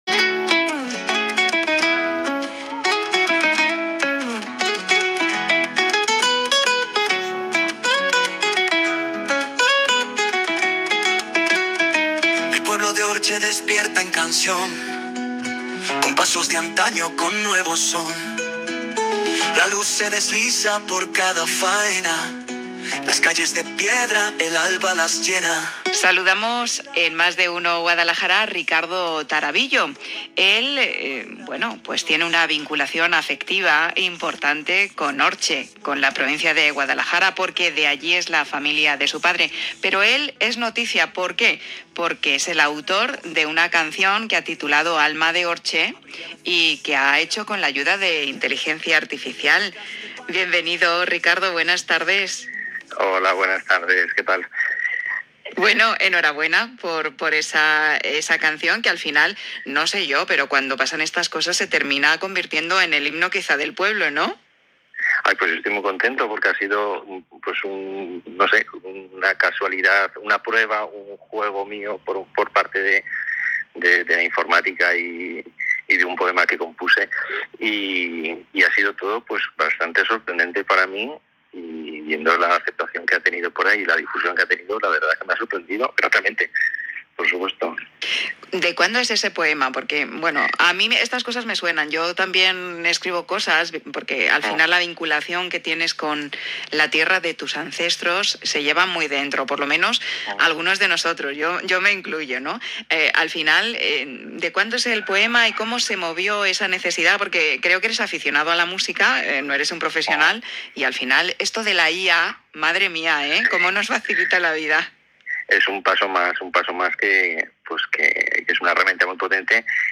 entrevista ONDA CERO.mp3
entrevista-onda-cero.mp3